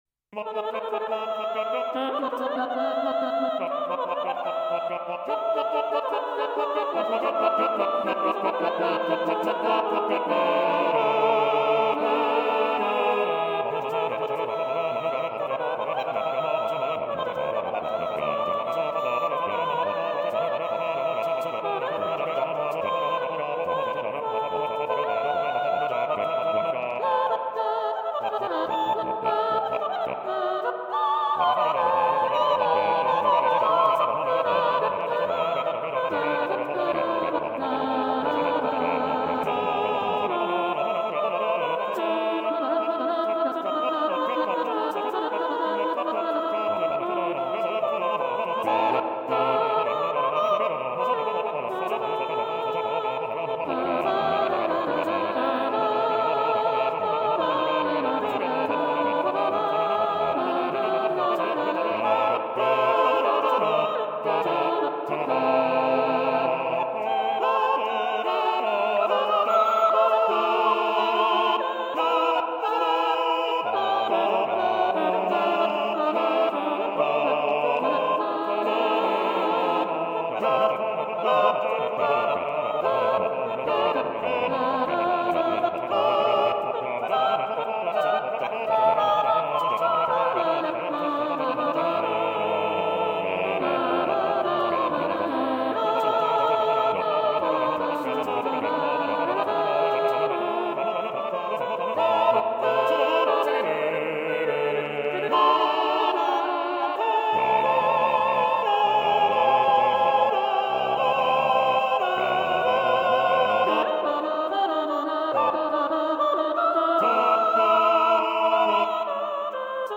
Blob Opera